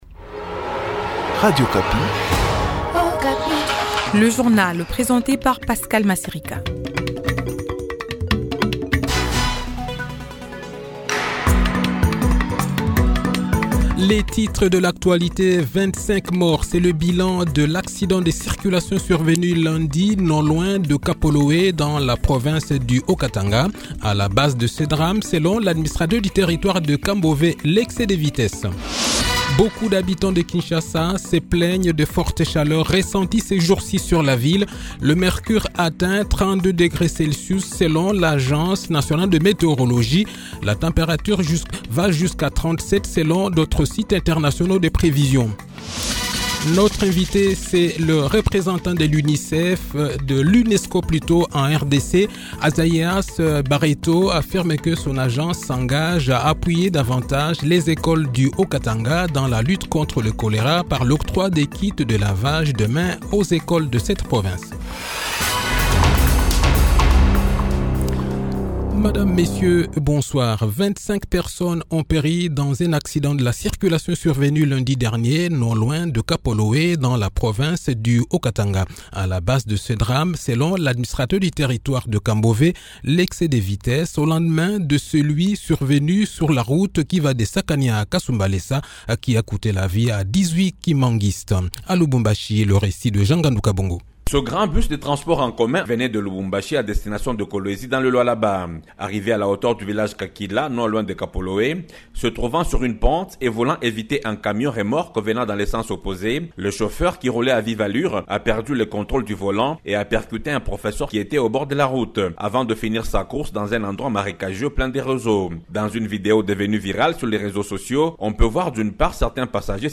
le journal de 18 h, 13 mars 2024